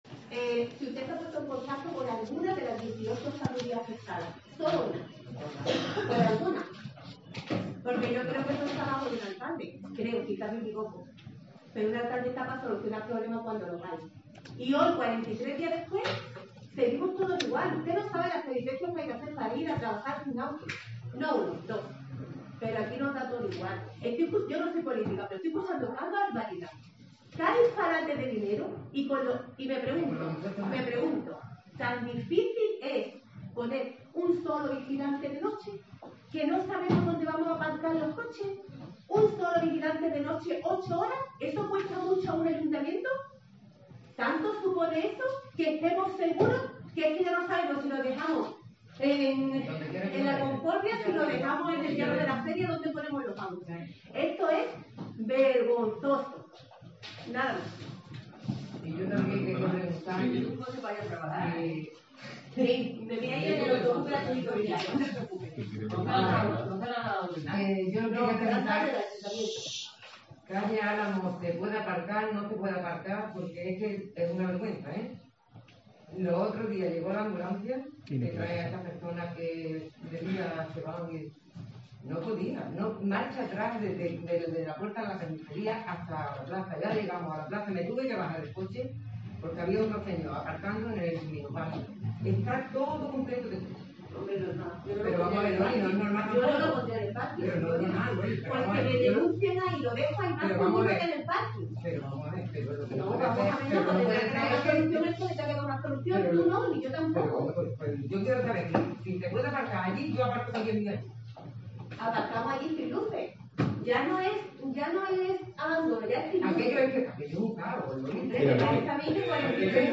Ha intervenido un vecino con varias preguntas, y un grupo de vecinas afectadas por el incendio en el Parking del Pontil.